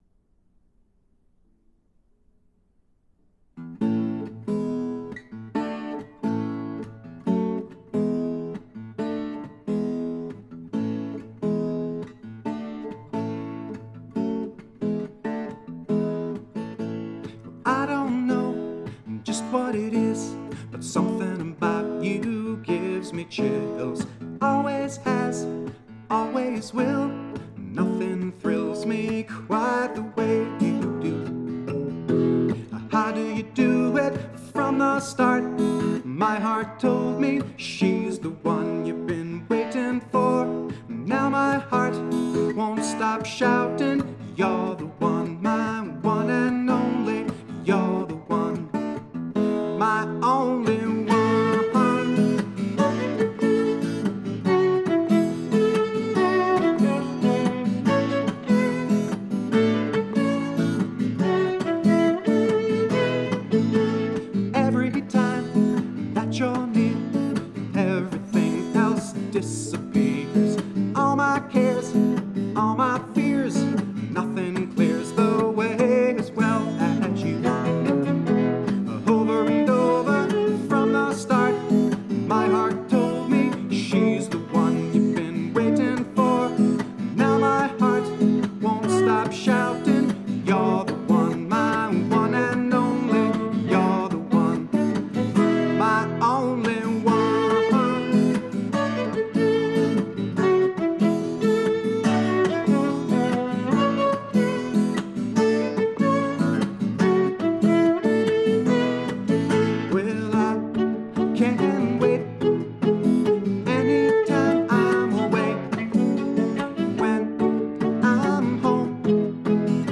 This love song
Besides my acoustic guitar and vocals
viola